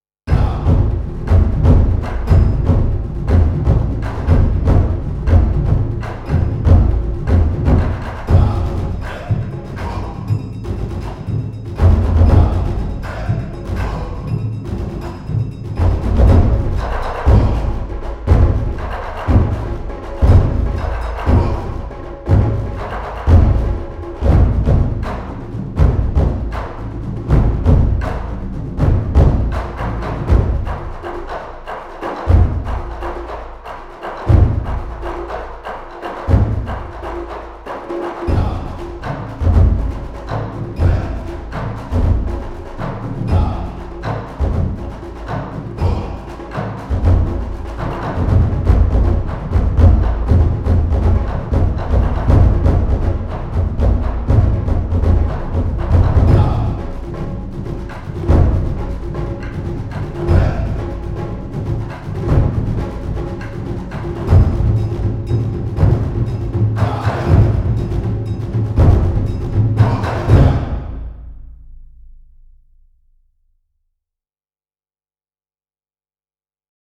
Thunderous percussion library
• Definitive collection of traditional Japanese taiko drums
Dramatic ensemble performances